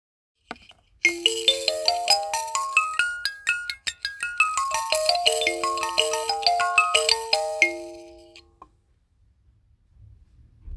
ザウォセのチリンバ極小 113一点物(※卵膜破れ有)【小売限定】
計算しつくされたバー とボディバランス、ホール部分にクモの卵膜を装着して荘厳な「ビビリ音」を実現。西洋近代楽器では表現しきれない「アフリカ特有の倍音」を再現できるスゴイ楽器です。
最高の音色、深い響き、本物志向、そのクオリティの高さはマニアもお墨付きです!
素材： 木・鉄・アルミ・皮・クモの卵膜